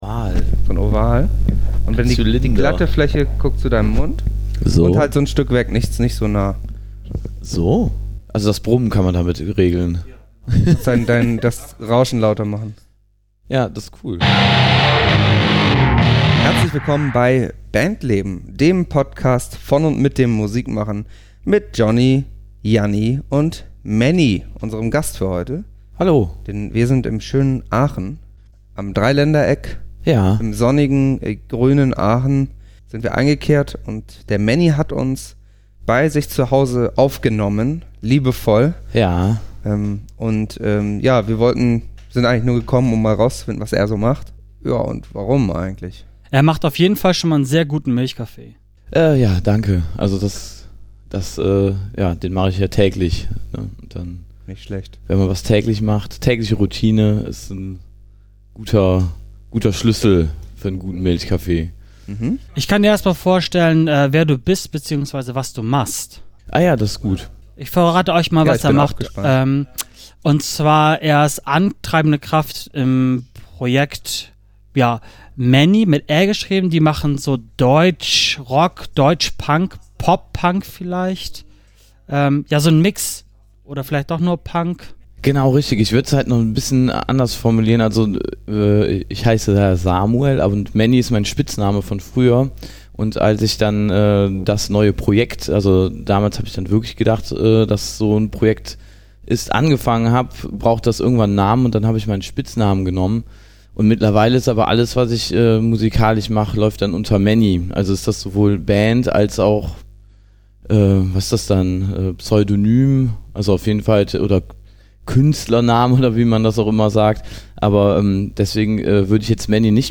*Die etwas schlechtere Audioqualität im Verhältnis zu sonst bitten wir zu entschuldigen, unser mobiles Setup muss noch etwas optimiert werden, wir arbeiten dran.